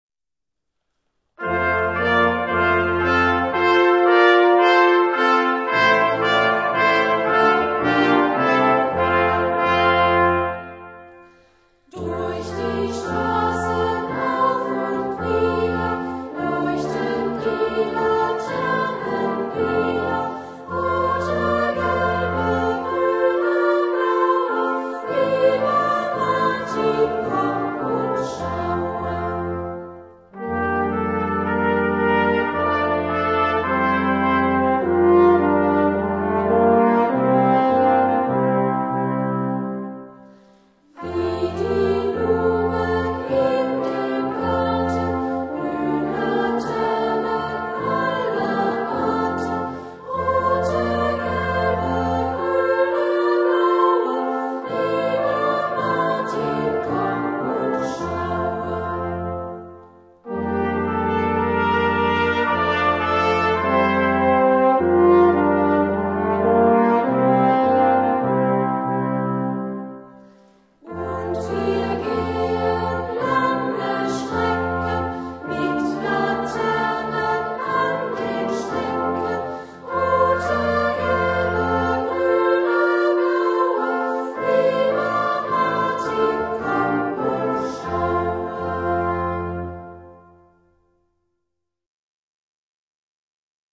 Instrumentalsätze